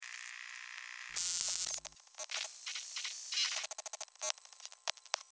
Звук зума прибора ночного видения при определении цели